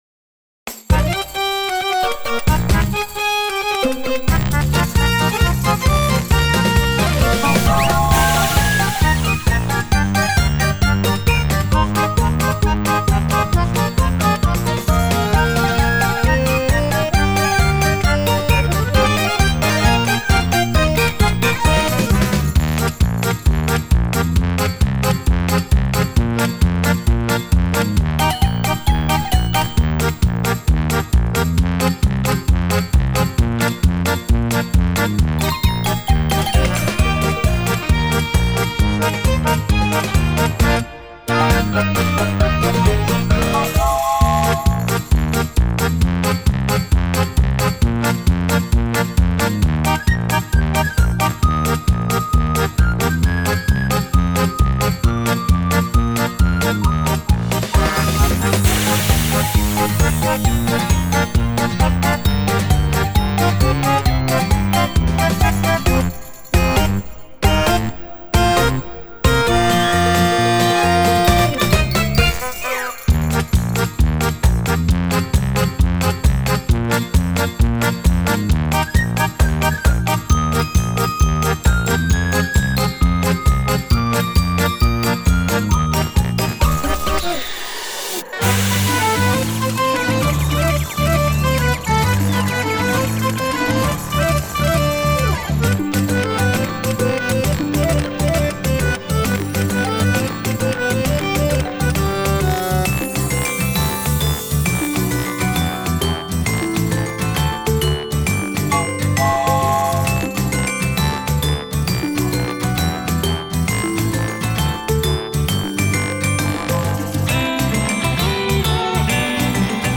インストゥルメンタル